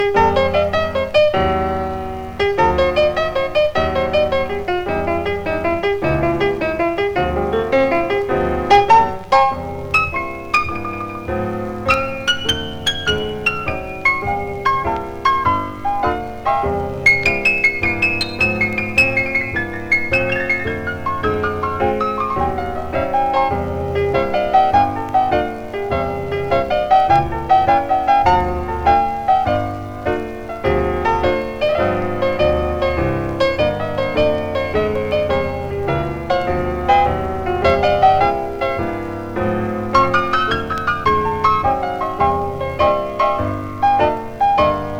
Jazz, Swing, Boogie Woogie　USA　12inchレコード　33rpm　Mono